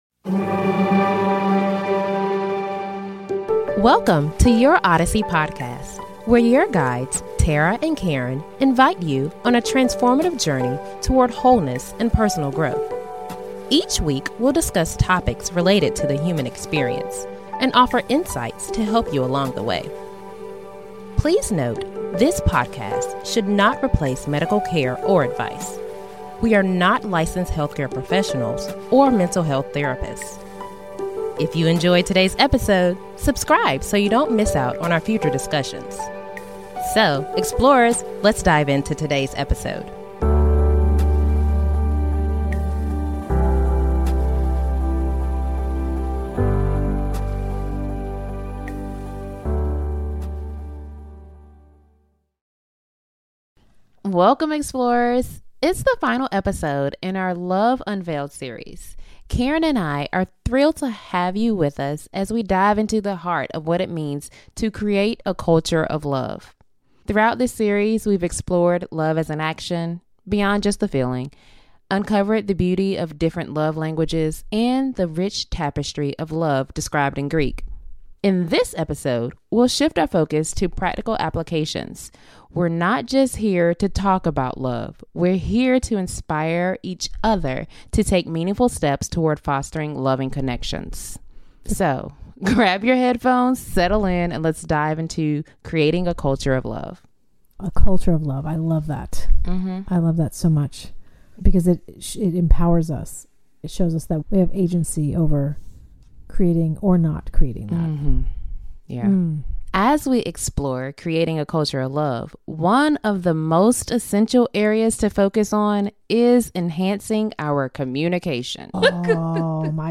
The discussion emphasizes enhancing communication, resolving conflicts with a loving mindset, and the importance of self-care. As part of Relationship Wellness Month, this episode aims to inspire listeners to build nurturing and supportive environments.